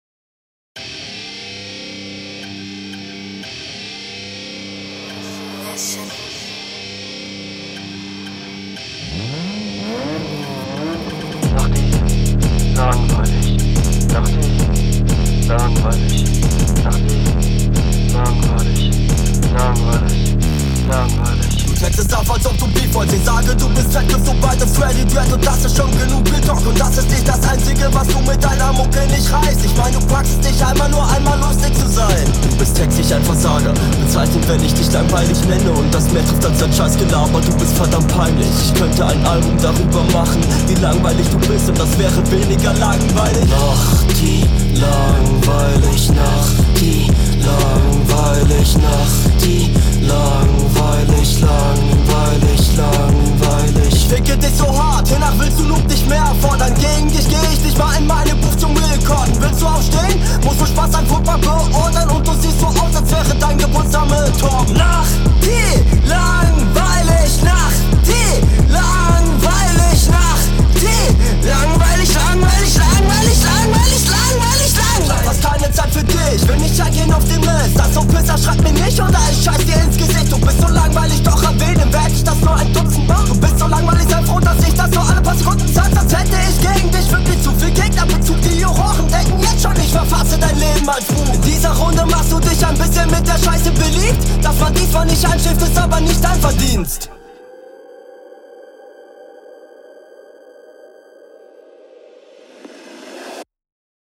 Flow: Flow sehr souverän. Kooler style, kooler stimmeinsatz, nur zu weit im beat versunken.